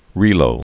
(rēlō)